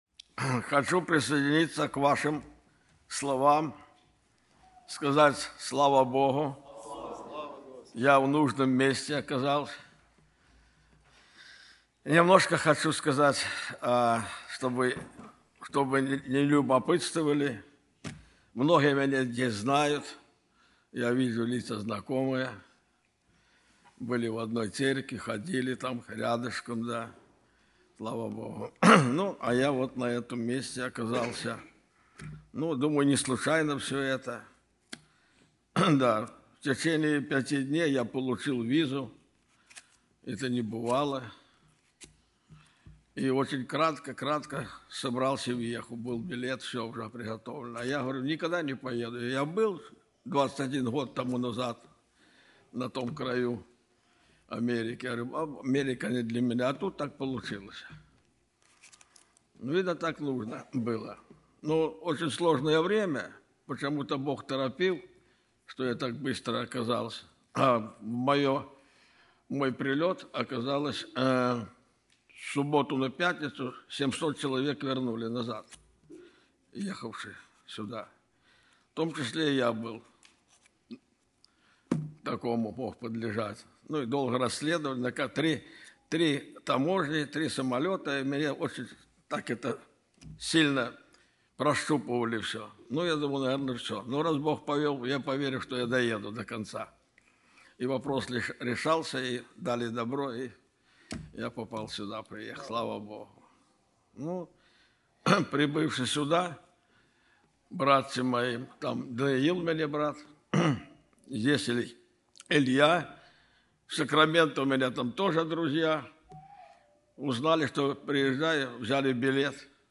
02-12-17 Sunday-06 Preacher 4A